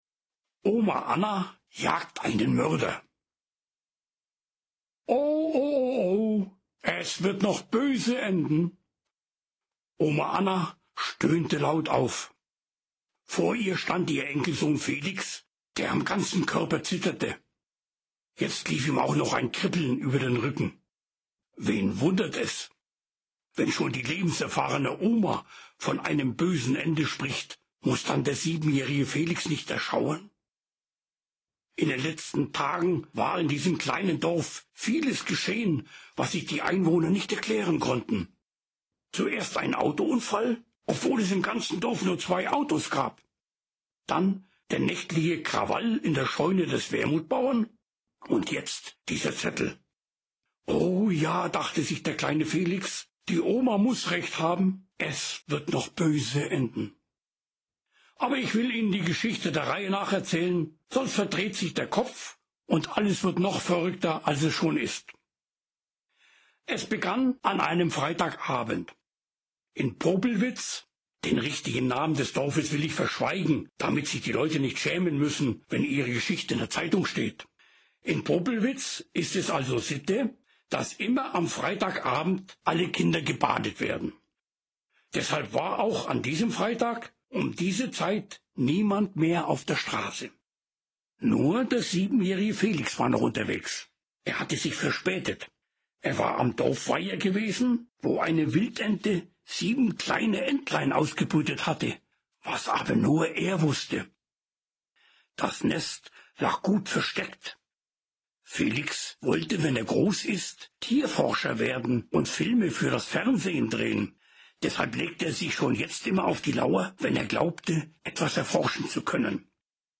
Hörprobe: Oma Anna jagt den Mörder